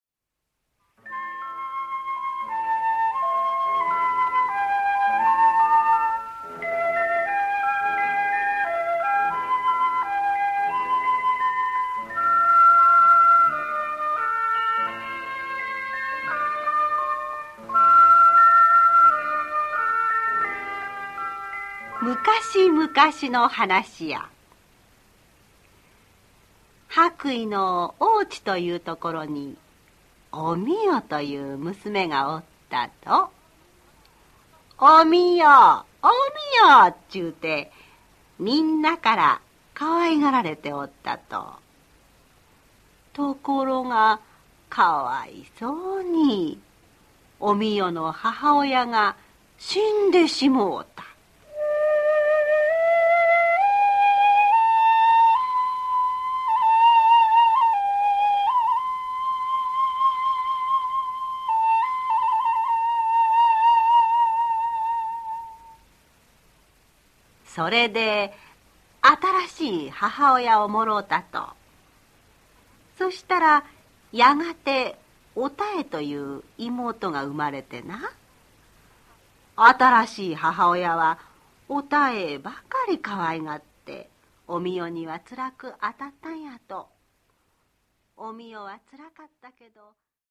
[オーディオブック] 千石のまめの木